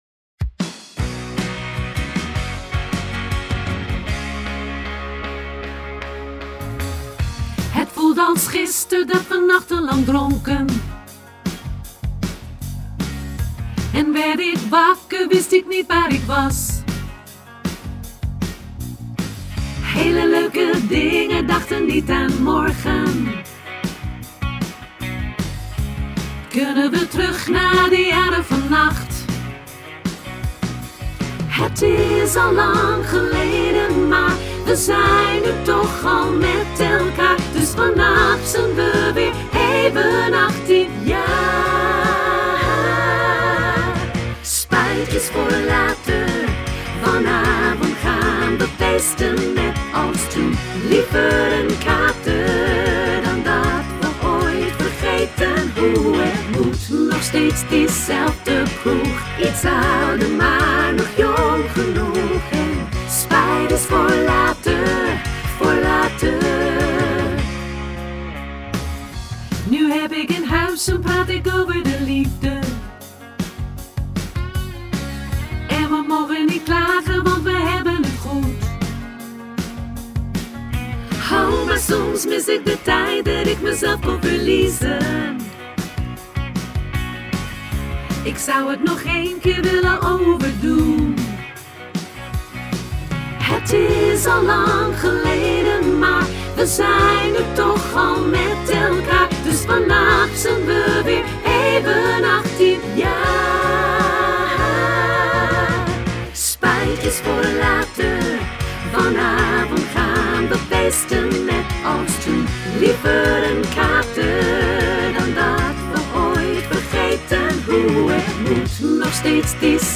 tutti